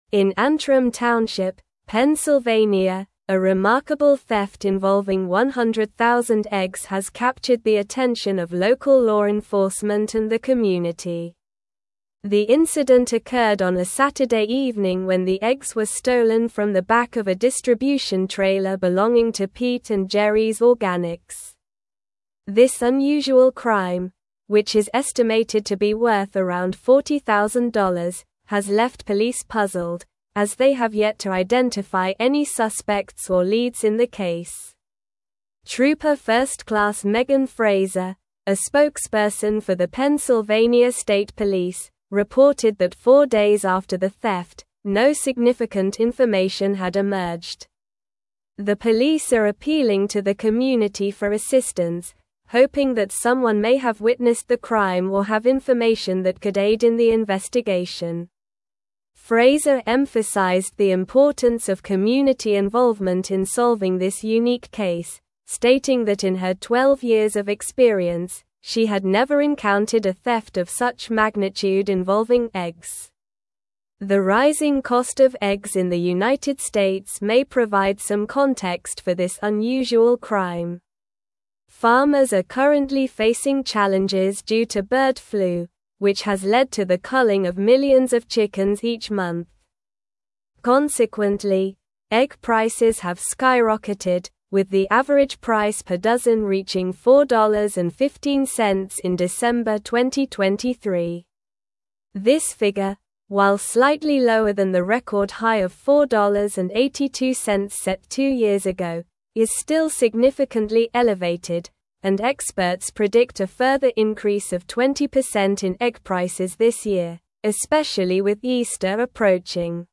Slow
English-Newsroom-Advanced-SLOW-Reading-Massive-Egg-Theft-Leaves-Pennsylvania-Authorities-Searching-for-Clues.mp3